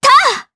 Laudia-Vox_Attack3_jp.wav